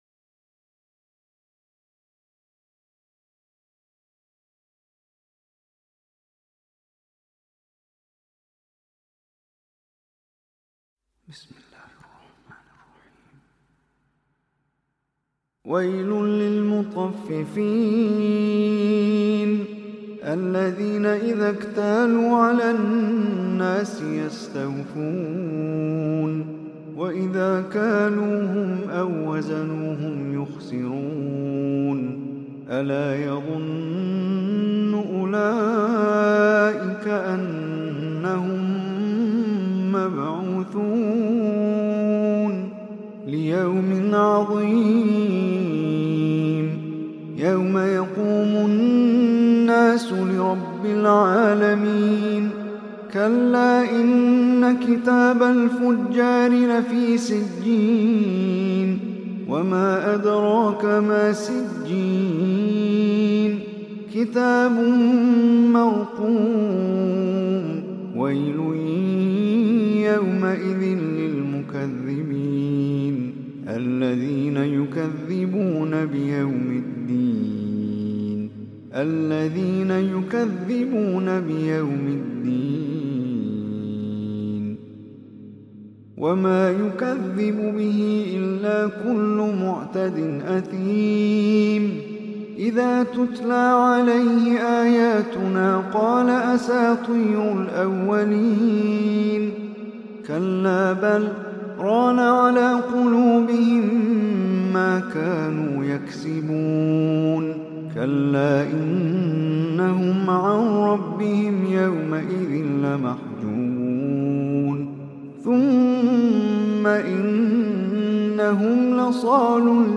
Surah Mutaffifin MP3 Recitation by Omar Hisham
Surah Mutaffifin, is 83 surah of Holy Quran. Listen or play online mp3 tilawat / recitation in arabic in the beautiful voice of Omar Hisham Al Arabi.
083-SURAH-AL-MUTAFFIFEEN.mp3